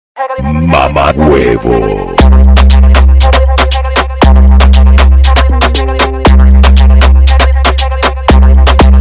mamaguevo bass Meme Sound Effect
Category: Meme Soundboard